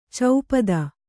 ♪ caupada